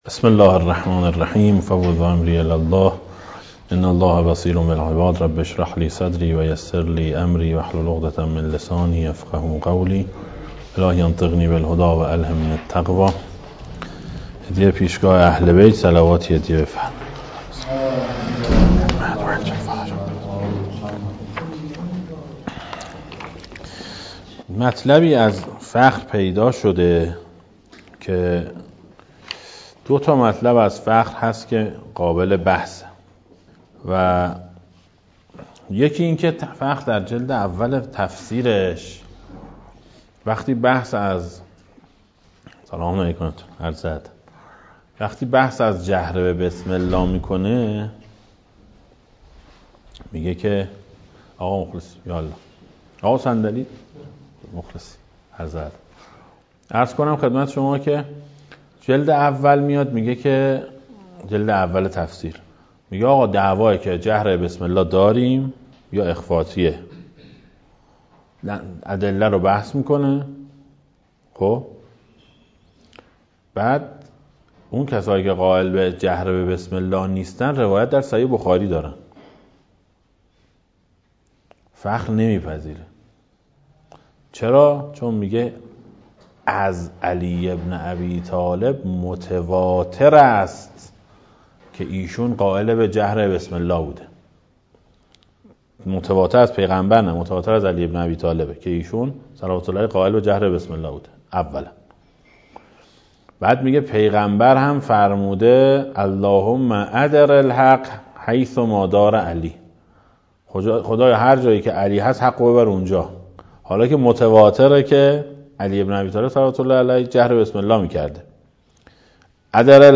در مدرس پژوهش حوزه علمیه امام خمینی (ره) تهران برگزار گردید